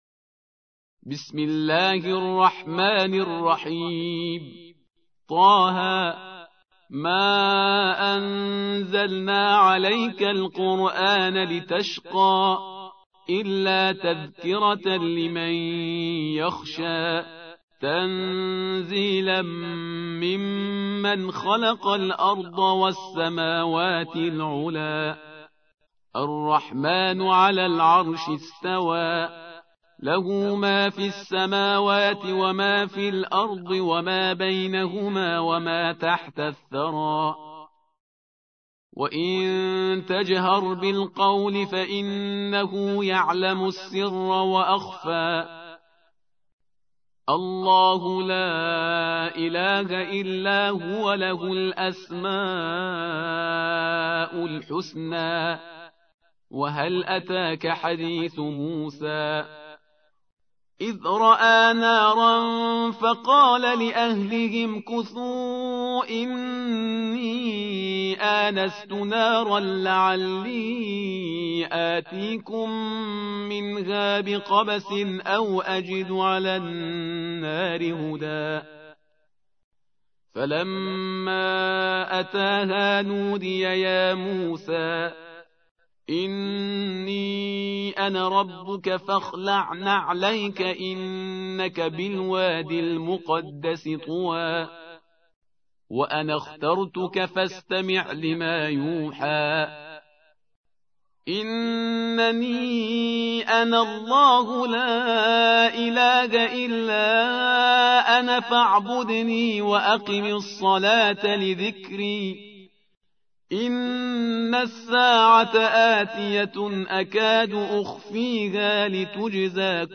20. سورة طه / القارئ